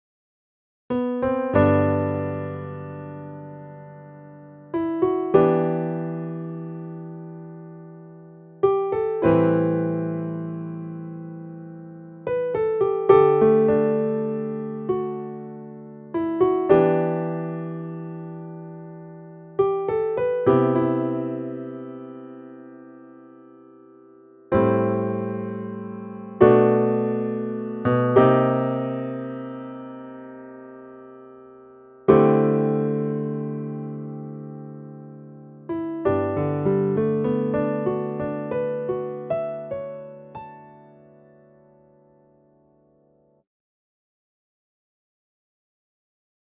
ピアノ曲